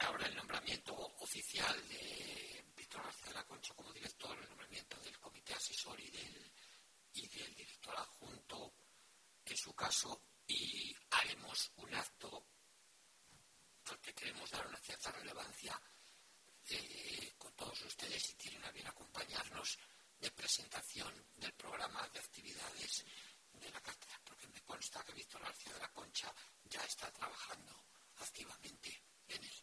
Declaraciones de Daniel Hernández Ruipérez en la rueda de prensa posterior a la sesión ordinaria de octubre del Consejo de Gobierno